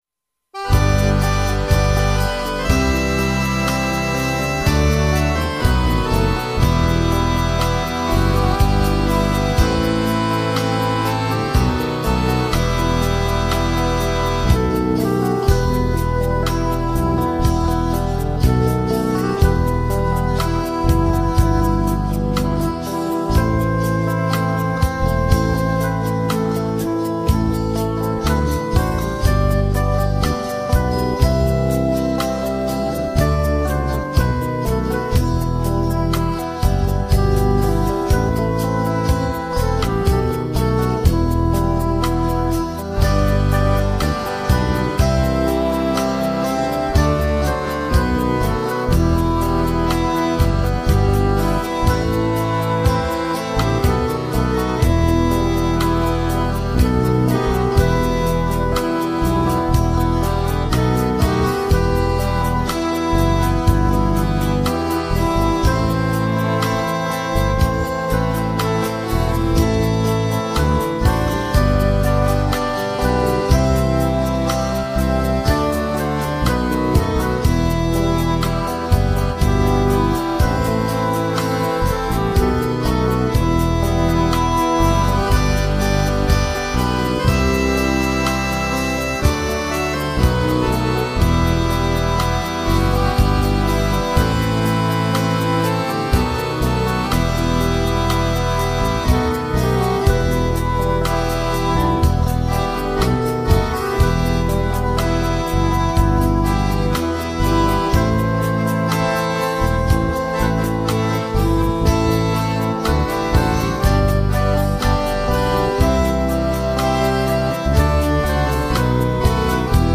narodnaya-chyornyj-voron-minus.mp3